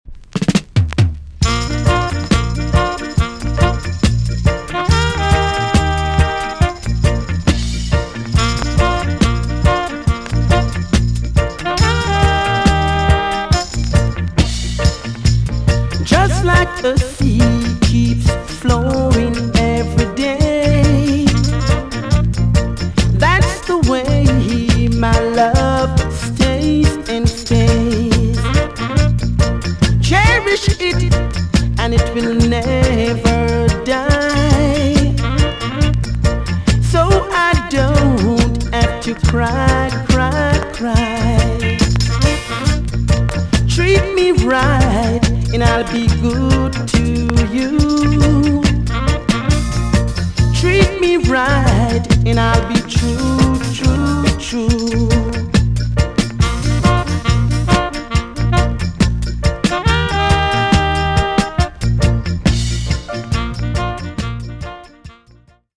TOP >REGGAE & ROOTS
EX- 音はキレイです。
NICE VOCAL TUNE!!